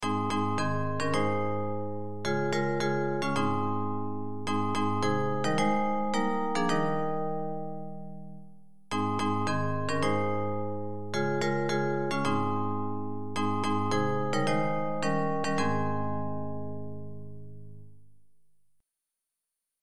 Bells Version
Music by: USA campmeeting melody;